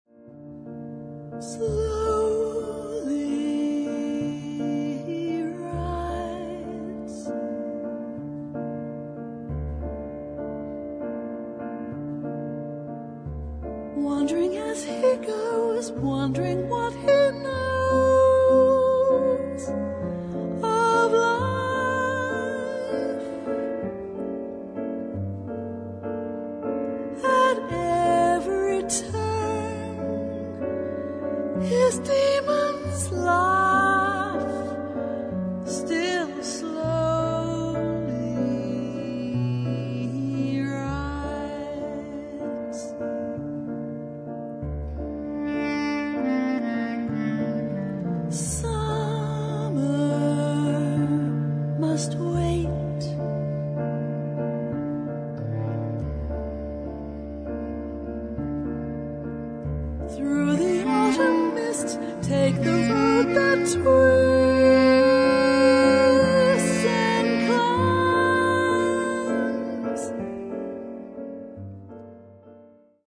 Recorded December 2009, Artesuono Studio, Udine
Piano
Sop. Sax, Bs Clt